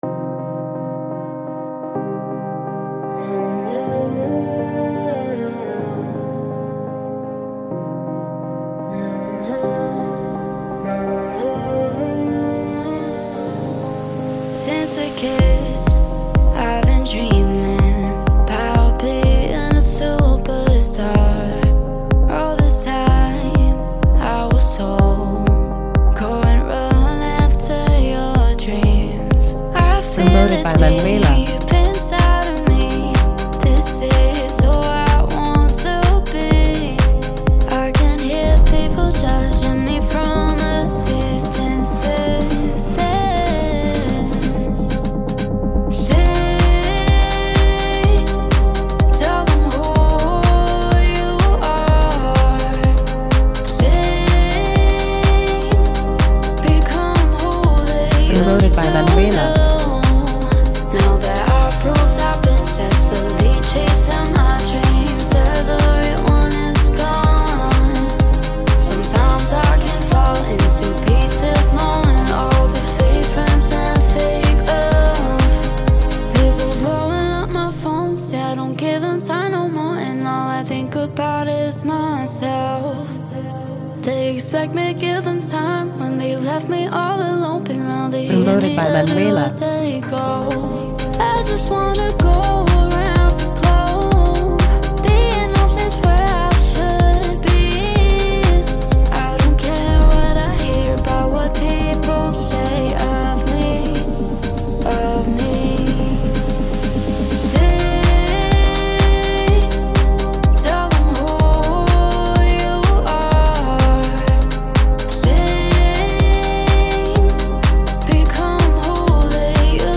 Radio Edit